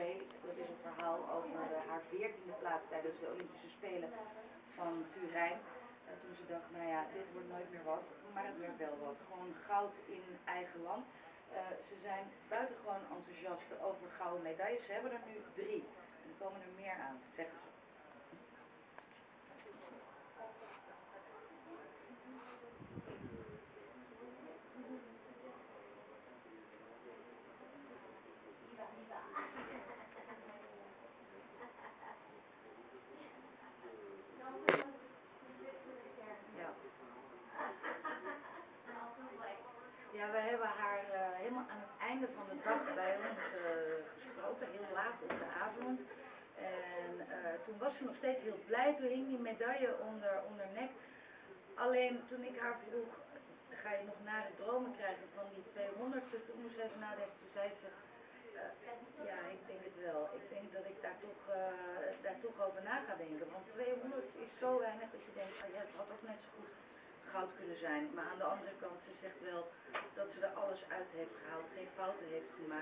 NOD live broadcast 2010 Olympics